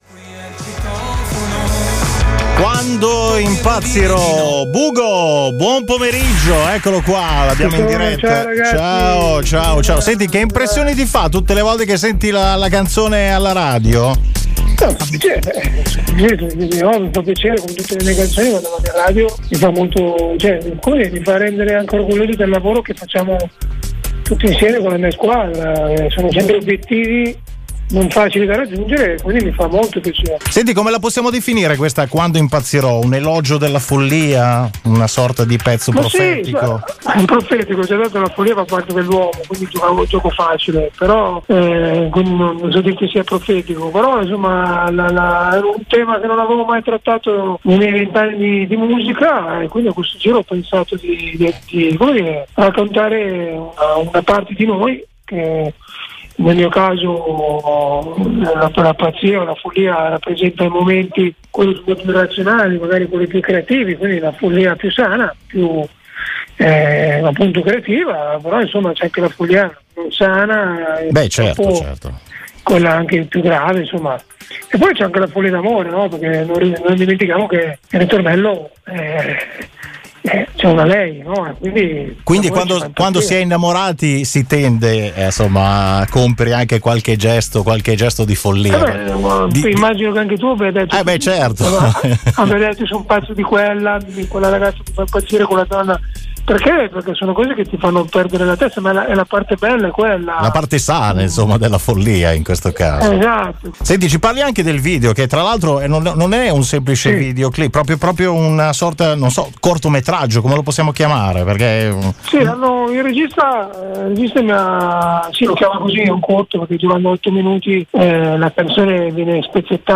Venerdì 5 febbraio il programma del pomeriggio, Senza Pretese, ha avuto il piacere di avere ospite telefonico BUGO! Il cantautore si è raccontato al nostro microfono, con particolare riferimento all’ultimo singolo “Quando impazzirò”, di cui ha ideato anche il video ufficiale, un vero e proprio corto di 8 minuti: